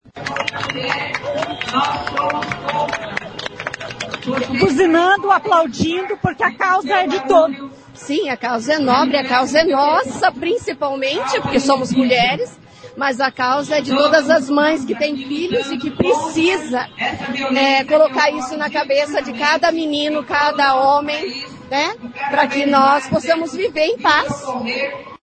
Foi realizada na manhã deste sábado (30) uma caminhada pelo fim da violência contra a mulher.
Durante a caminhada, motoristas passaram buzinando e pedestres aplaudindo.